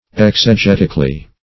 Ex`e*get"ic*al*ly , adv.